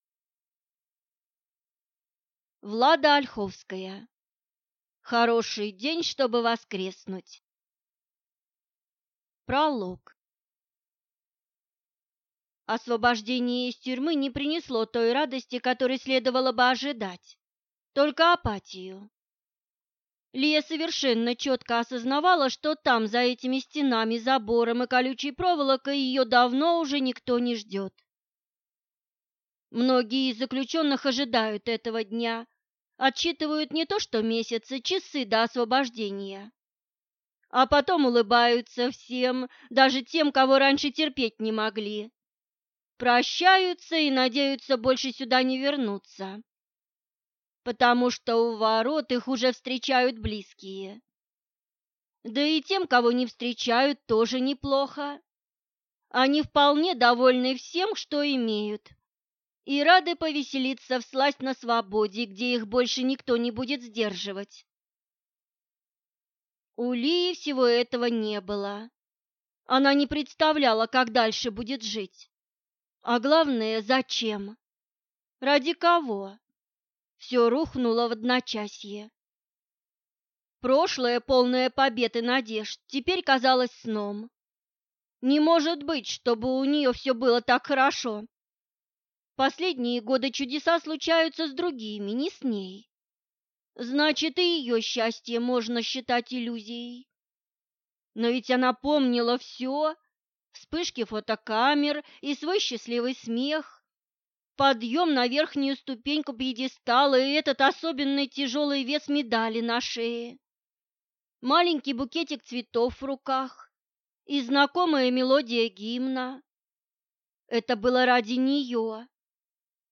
Aудиокнига Хороший день, чтобы воскреснуть
Читает аудиокнигу